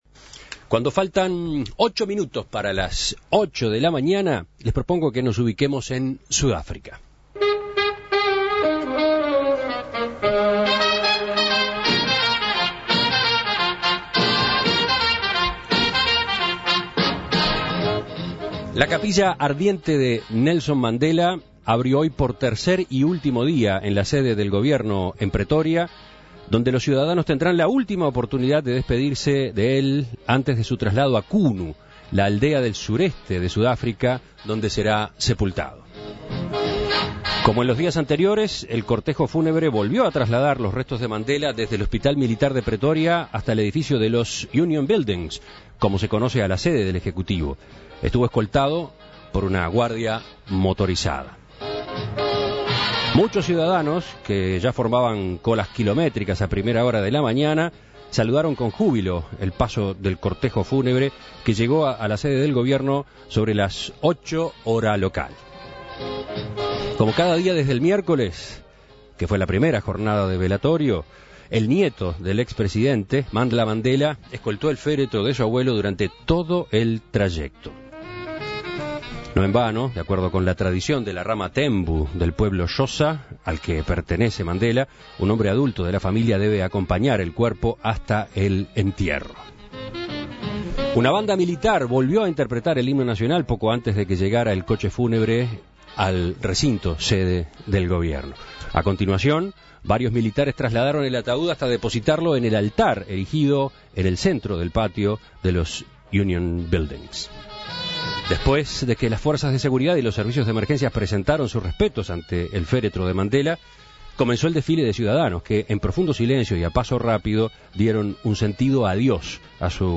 En Perspectiva dialogó con Daniel Castillos embajador uruguayo en Sudáfrica, quien ha participado en las actividades de los días pasados.